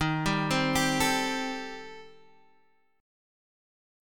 Eb6b5 chord